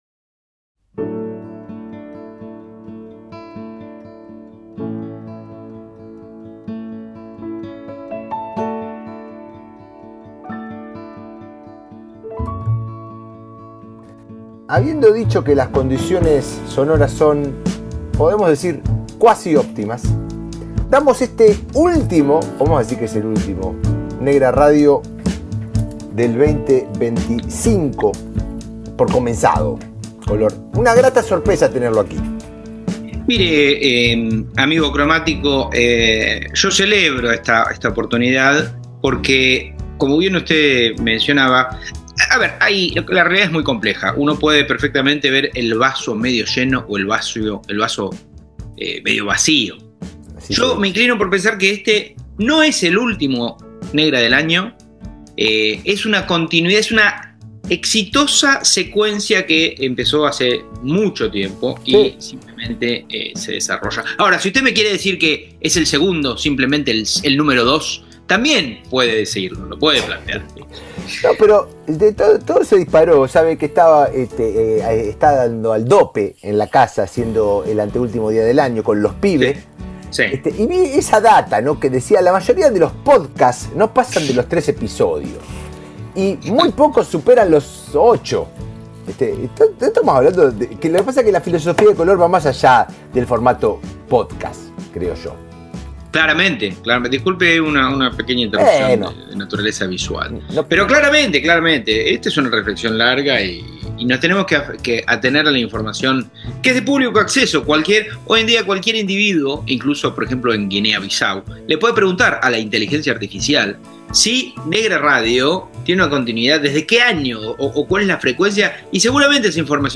A través de charlas, recuerdos, referencias y momentos musicales, el programa se adentra en los caminos que forman parte de la filosofía de color : las influencias que marcan, los contextos que moldean y las preguntas que siguen abiertas.